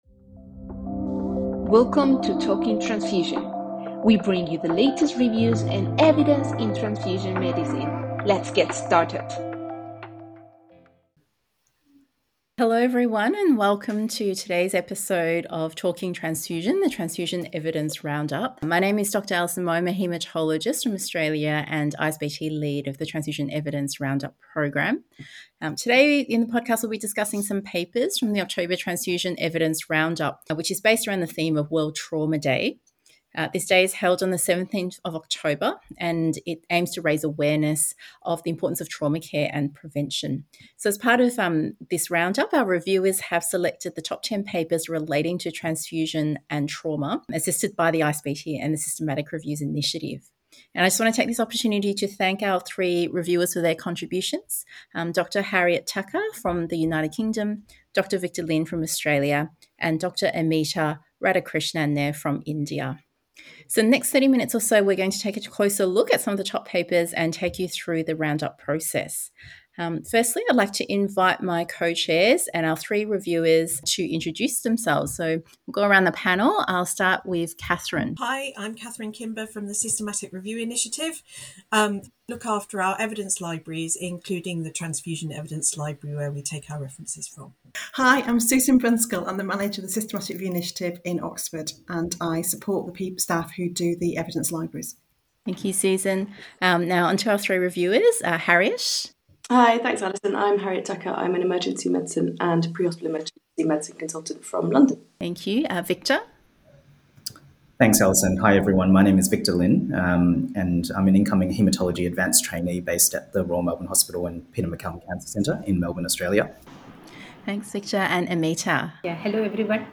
We discuss the latest research on massive transfusion protocols, the management of bleeding in trauma settings, and the evolving strategies for blood component therapy. Our panel explores how these findings can impact real-world clinical decisions, enhancing patient outcomes in emergency trauma situations.